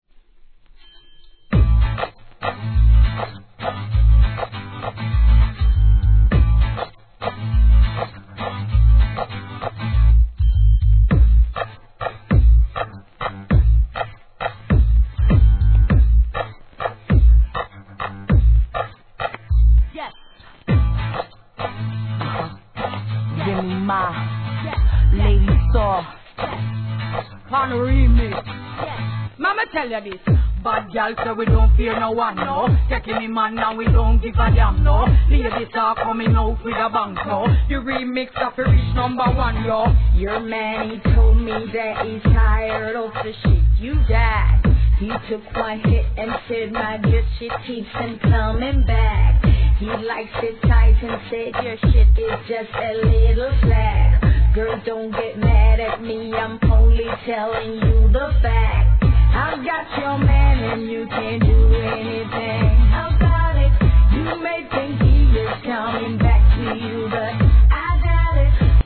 REGGAE
ギター・リフの効いた軽快な好JUGGLIN